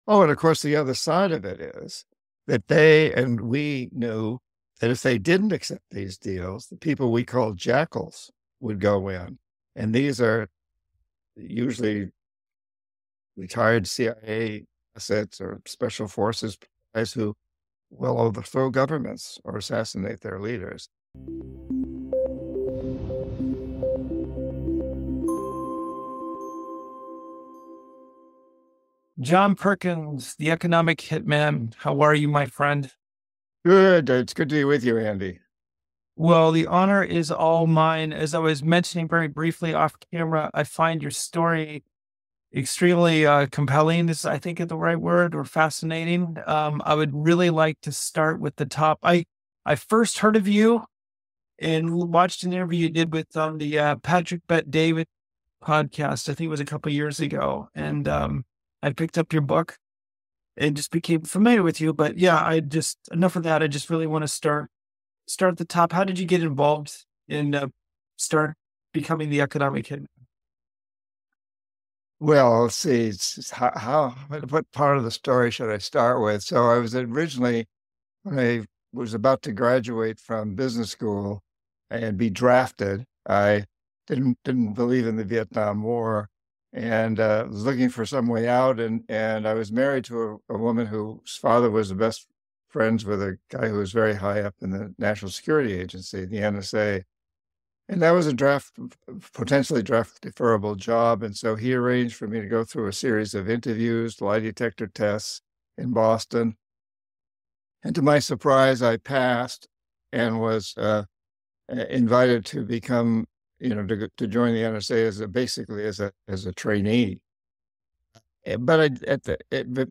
interviews John Perkins, a former economic hitman, who shares his compelling and fascinating journey.